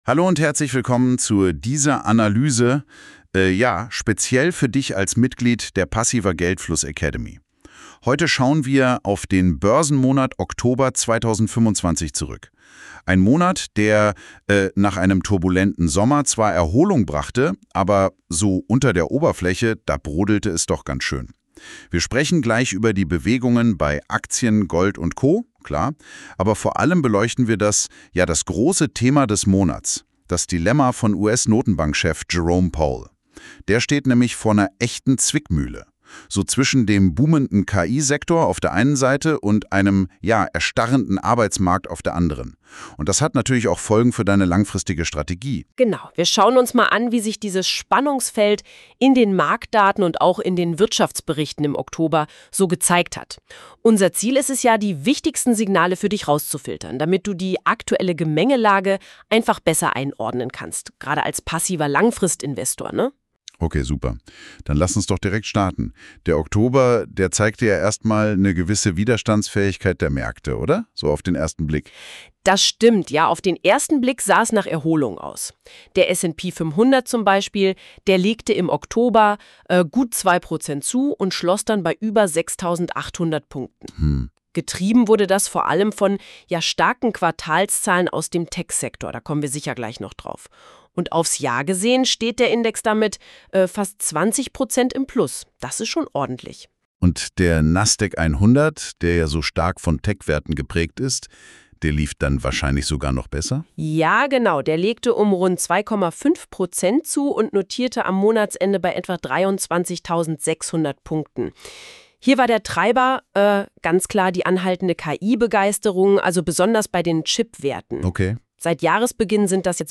(nächste Aktualisierung: 01.12.2025) Was hat im Oktober 2025 die Börse bewegt und was waren wichtige Wirtschaftsereignisse? Zwei KI-Moderatoren fassen die relevanten Geschehnisse aus dem Oktober 2025 zusammen.